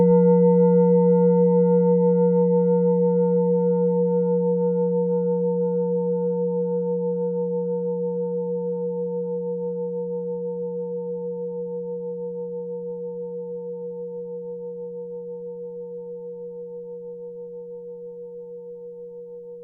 Klangschale Orissa Nr.10
Klangschale-Gewicht: 1050g
Klangschale-Durchmesser: 19,0cm
Sie ist neu und wurde gezielt nach altem 7-Metalle-Rezept in Handarbeit gezogen und gehämmert.
(Ermittelt mit dem Filzklöppel oder Gummikernschlegel)
klangschale-orissa-10.wav